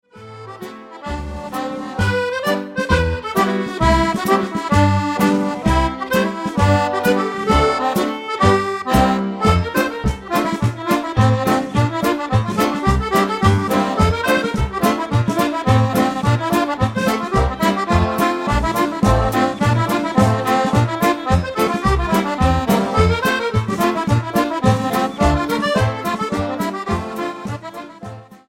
48S+48R Medley